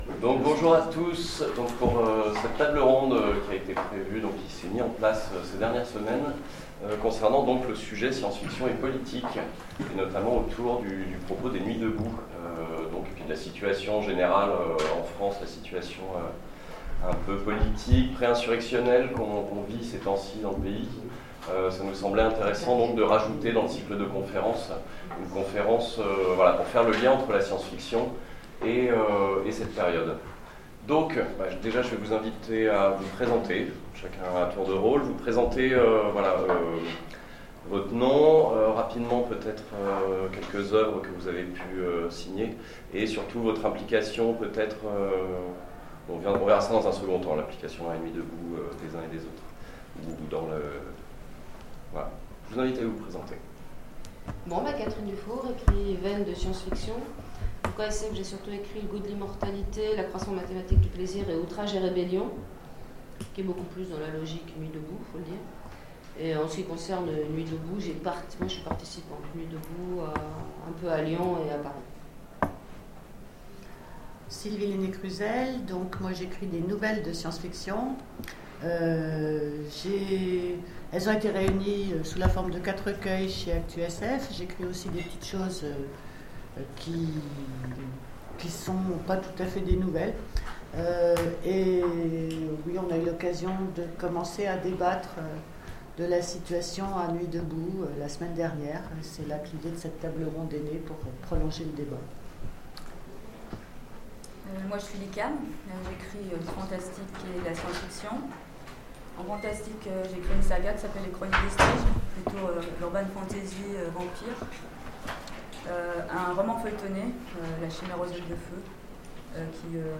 Intergalactiques 2016 : Table ronde Les nuits debout
Conférence